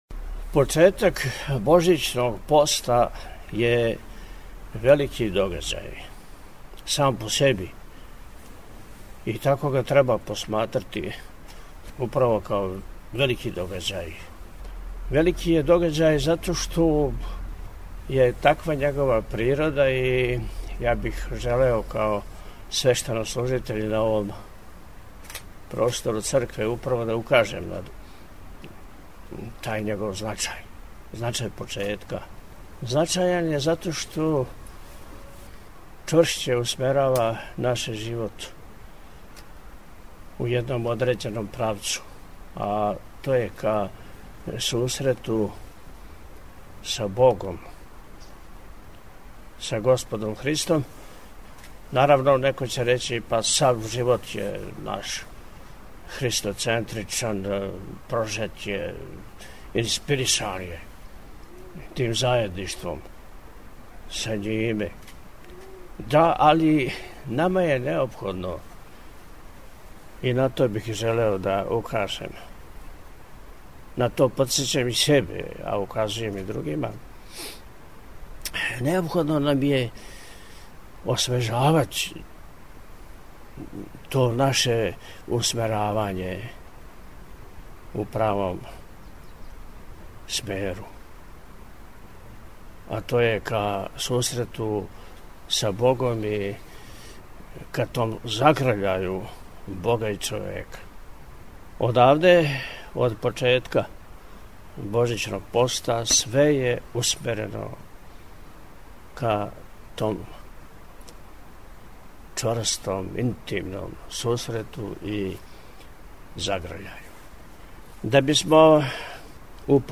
Његово Високопреосвештенство Архиепископ и Митрополит милешевски г. Атанасије упутио је, у четвртак 28. новембра 2024. године, из манастира Милешеве, архипастирску поруку верном народу на почетку Божићног поста.